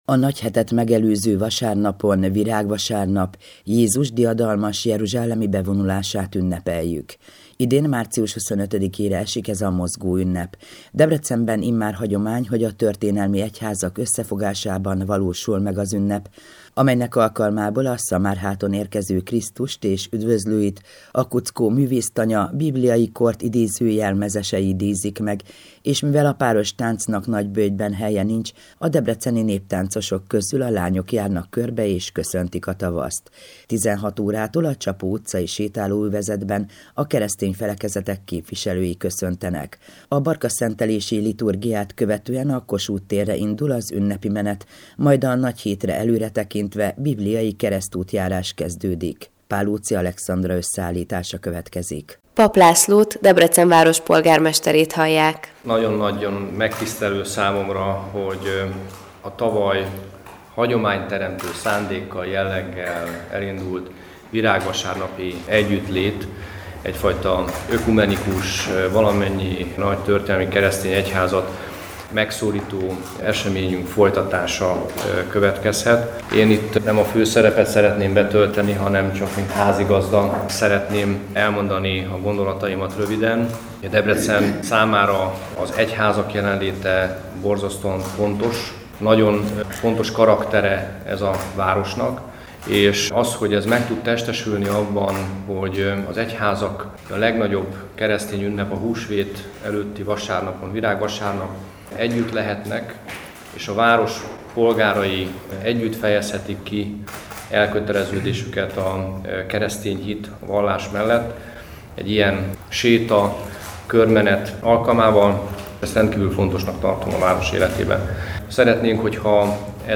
Elhangzottak többek között Bach, Mozart, Händel és Mendelssohn művei, de a modern zenei repertoárból is ízelítőt kapott a gyülekezet.
Az Európa Rádió összeállítása a debreceni virágvasárnapról: